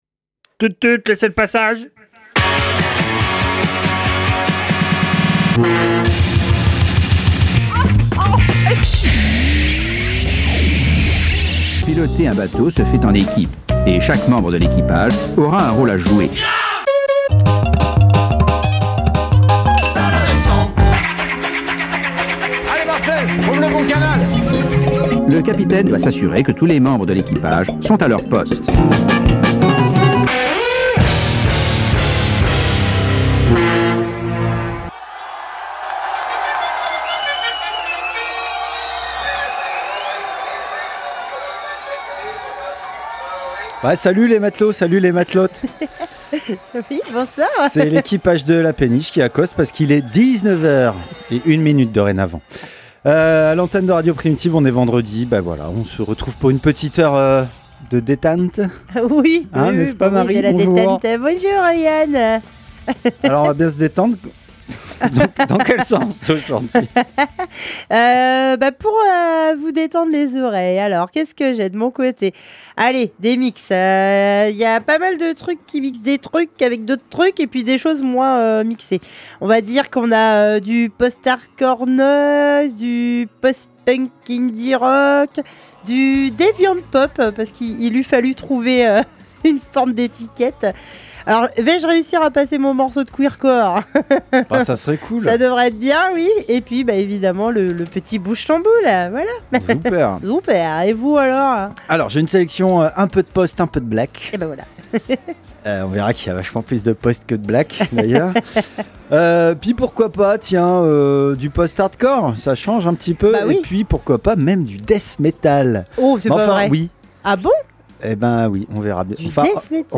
🎧 Péniche 14x21 - Péniche radio show